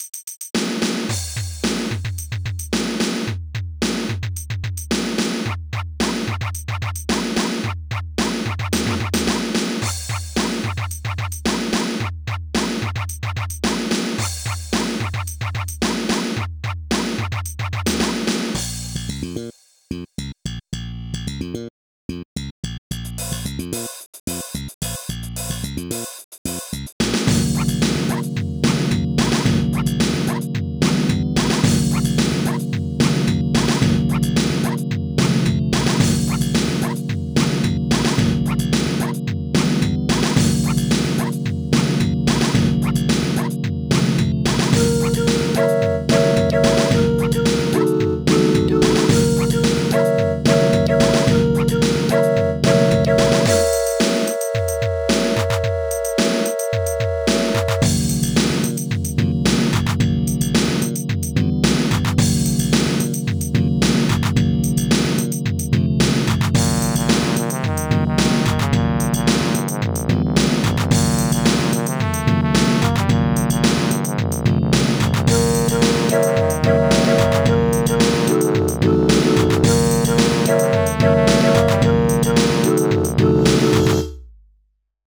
made a pizza tower ish song in the midi